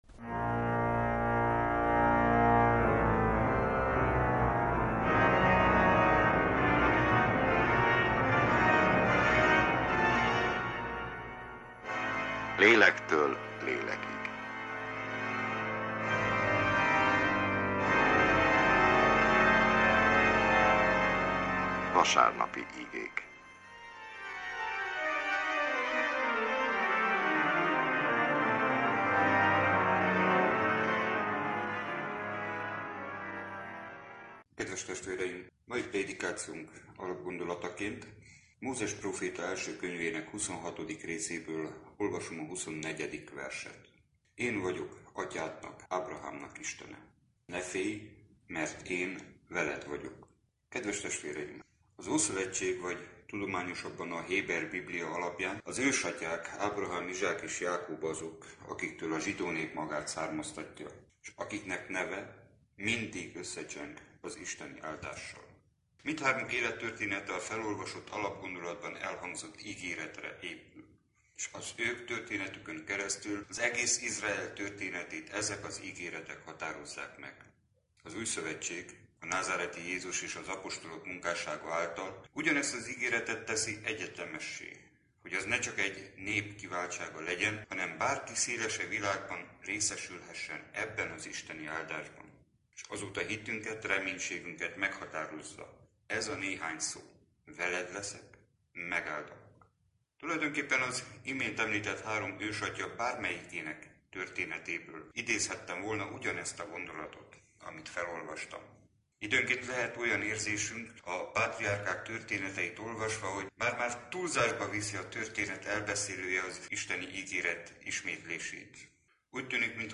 Unitárius igehirdetés, február 11.
Egyházi műsor